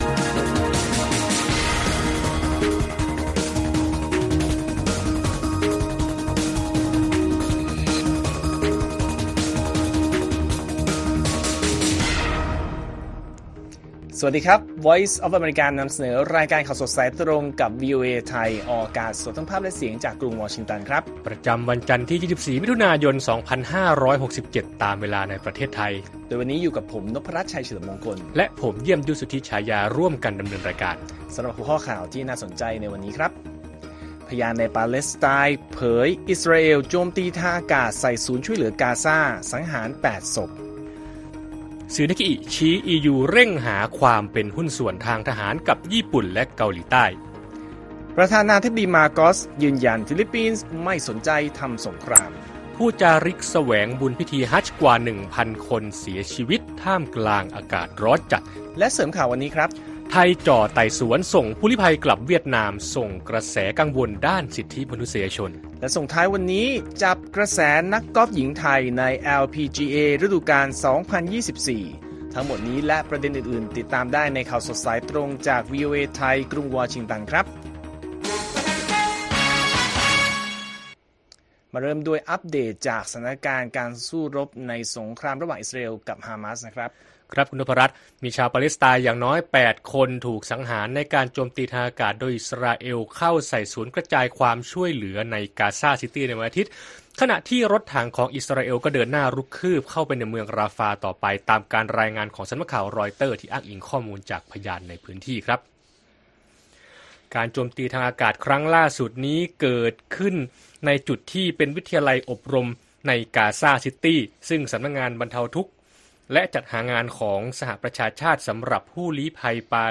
ข่าวสดสายตรงจากวีโอเอไทย จันทร์ ที่ 24 มิ.ย. 67